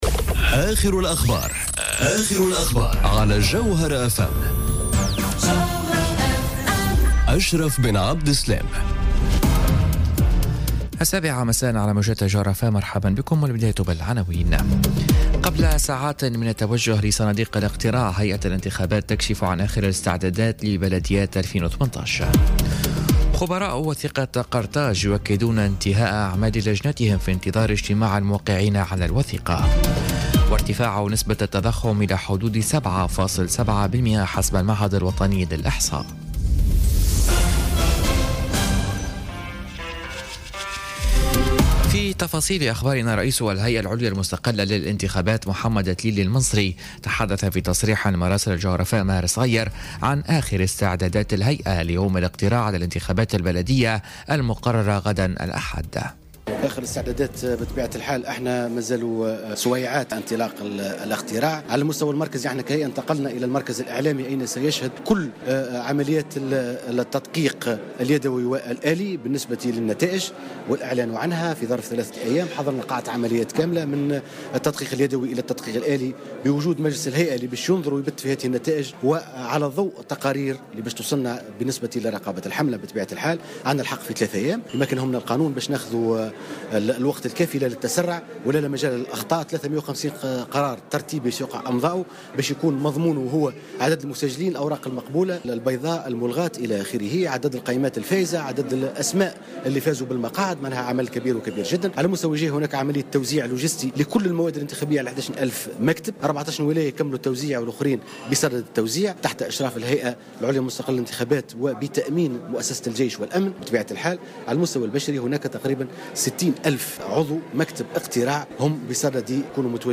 نشرة أخبار السابعة مساء ليوم السبت 5 ماي 2018